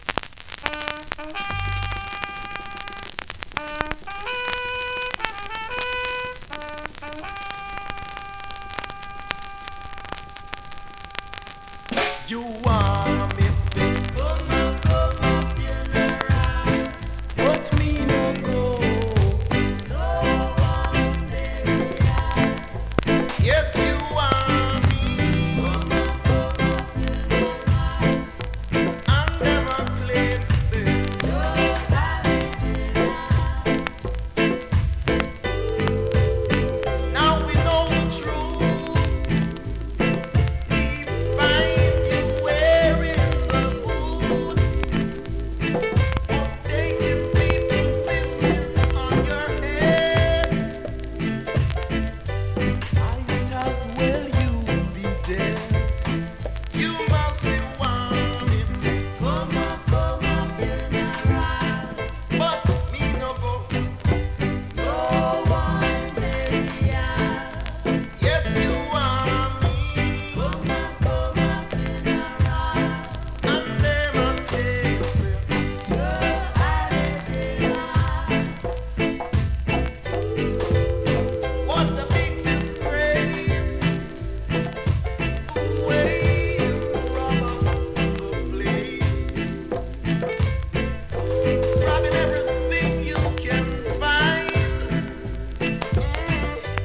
コメントレアROCKSTEADY!!
スリキズ、ノイズそこそこあります。